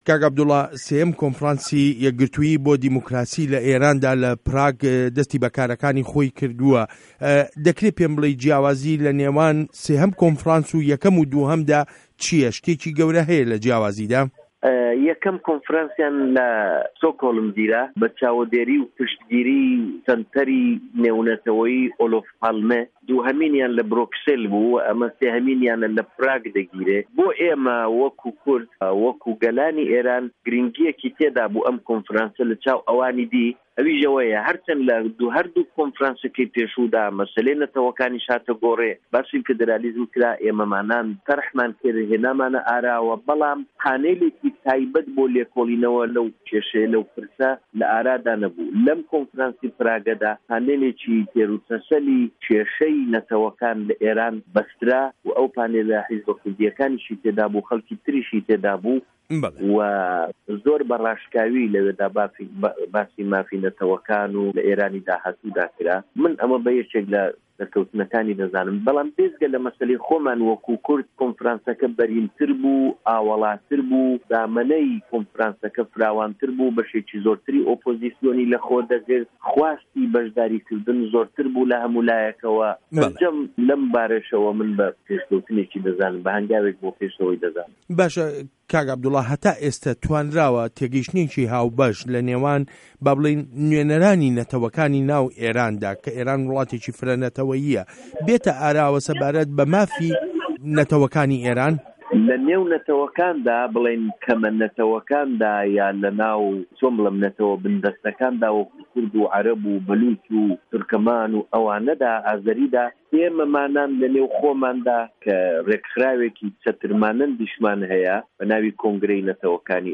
وتووێژ له‌گه‌ڵ عه‌بدوڵای موهته‌دی